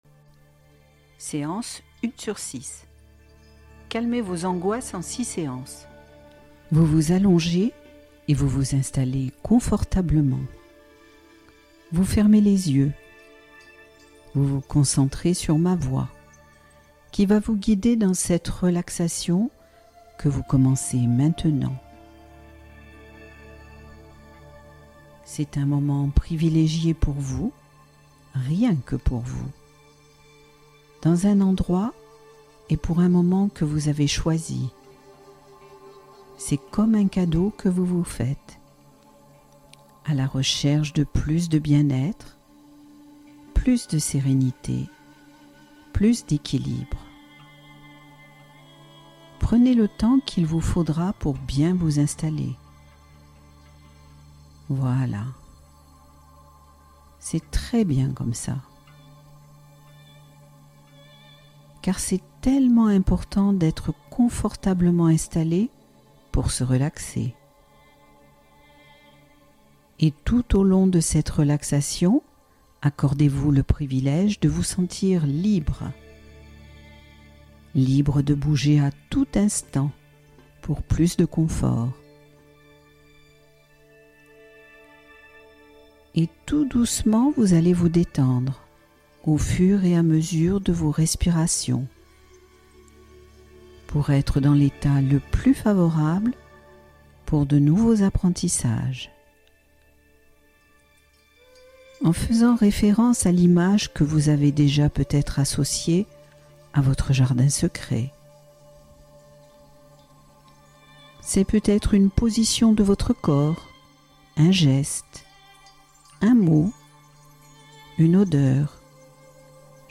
Angoisses calmées en 20 minutes : l'auto-hypnose d'urgence à écouter en cas de crise